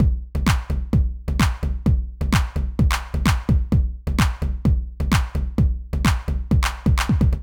INT Beat - Mix 18.wav